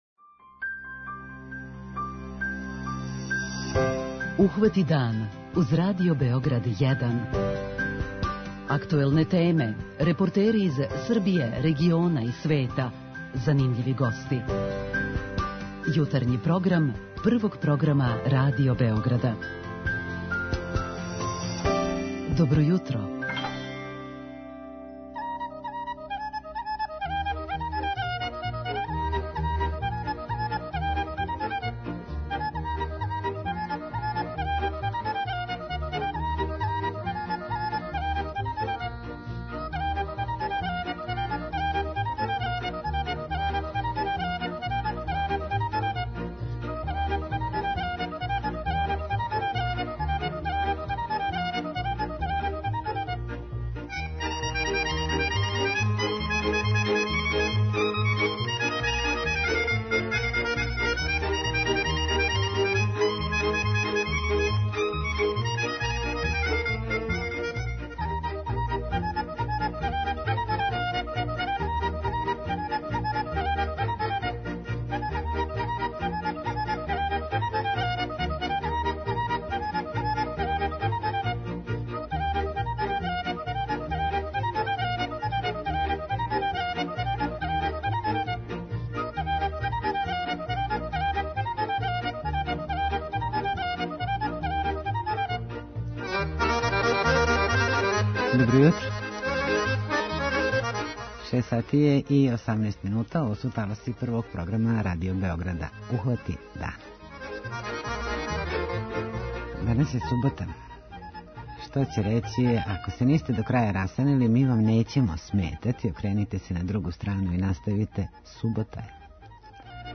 Шта су све припремили и кога све очекују, чућете од председника општине, Радета Васиљевића.
преузми : 29.39 MB Ухвати дан Autor: Група аутора Јутарњи програм Радио Београда 1!